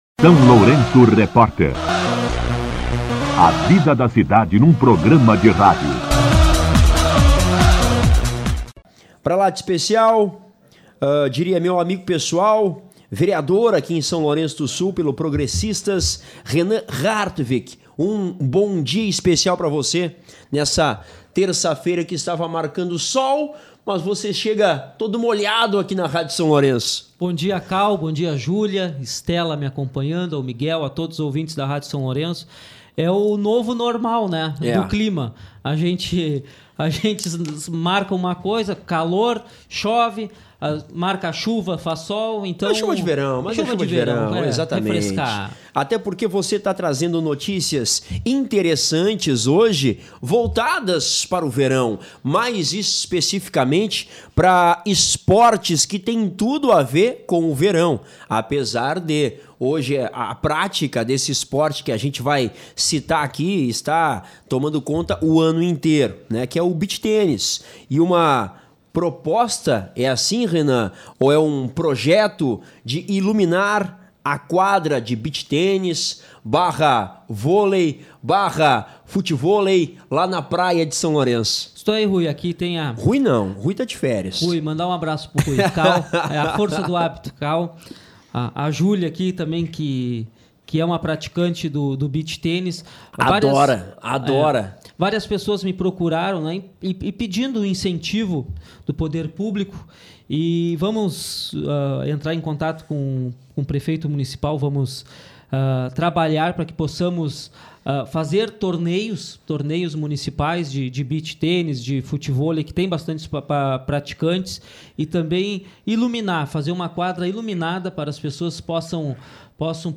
Entrevista com o vereador Renan Harwig (PP)